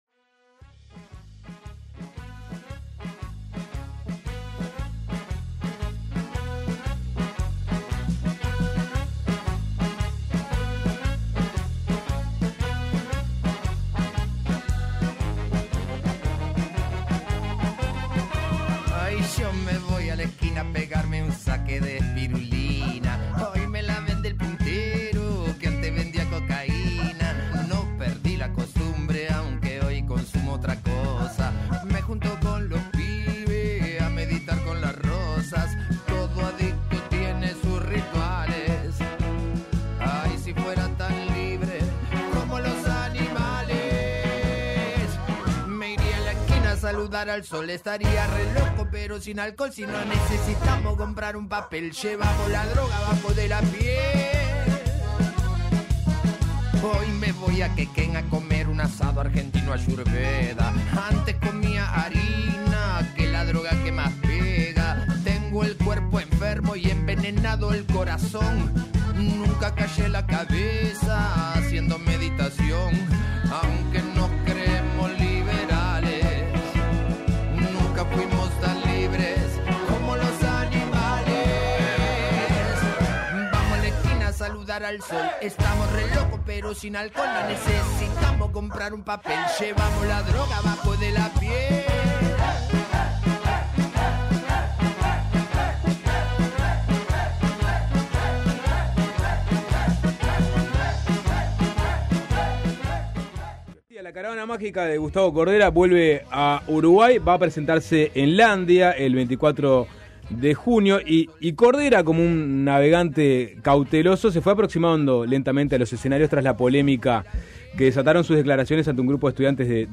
El músico argentino dijo a Suena Tremendo que sus dichos fueron "editados" y que muchos pasajes de la charla quedaron afuera, deformando totalmente su intención. También afirmó que la justicia actuó bajo presión social y consideró que los artistas están "indefensos" ante la "libertad de condena".